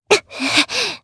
Sonia-Vox_Damage_jp_01.wav